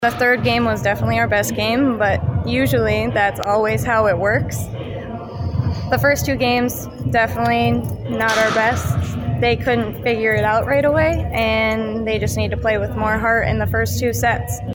post-game comments